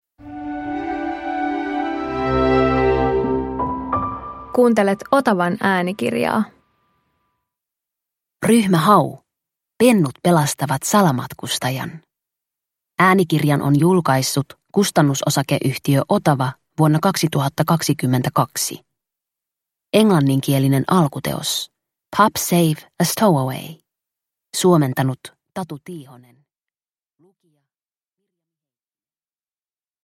Ryhmä Hau - Pennut pelastavat salamatkustajan – Ljudbok – Laddas ner